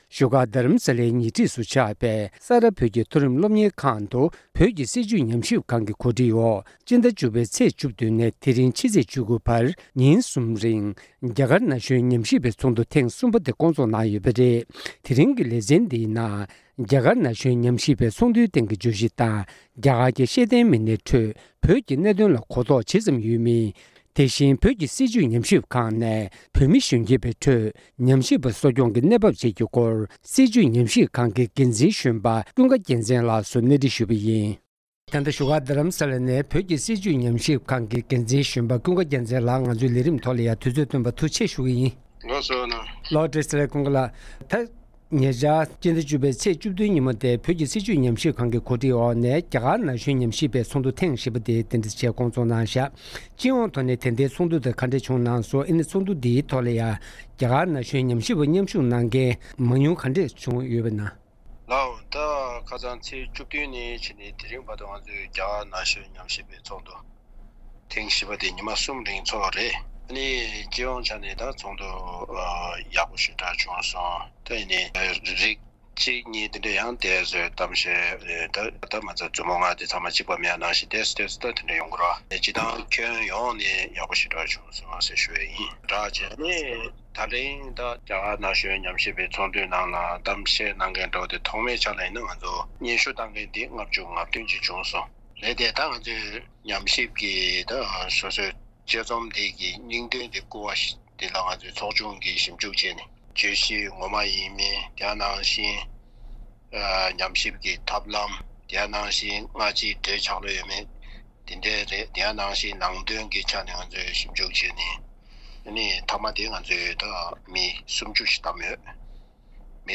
བཅར་འདྲི་ཕྱོགས་བསྒྲིགས་ཞུས་པར་གསན་རོགས་ཞུ།